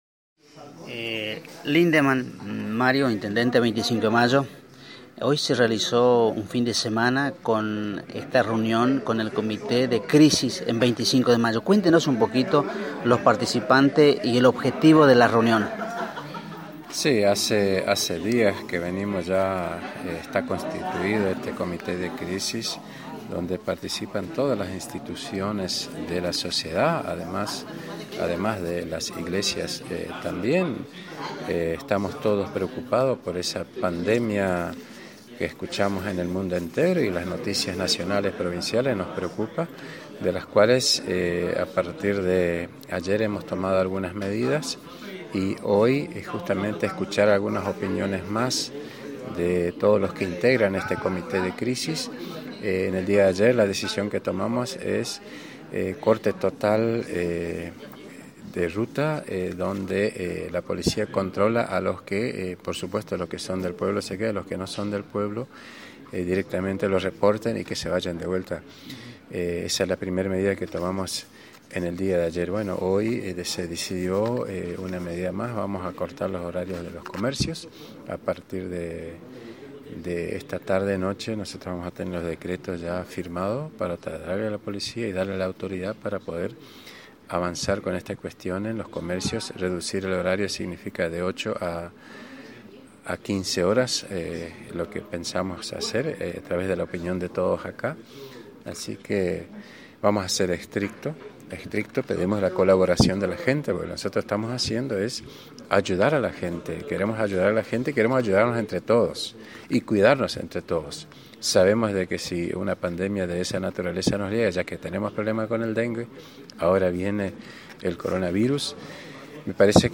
En la oportunidad el Intendente Mario Lindemann brindó detalles del encuentro» hace días hemos constituido está comisión dónde participan todas las instituciones de la sociedad, además de las iglesias también.